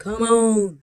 18 RSS-VOX.wav